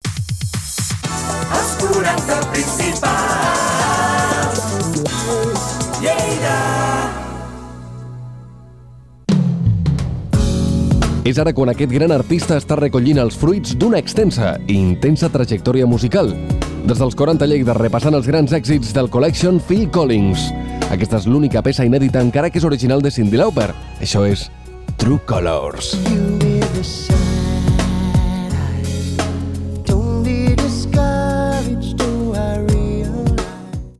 Indicatiu de l'emissora, presentació d'un tema musical
FM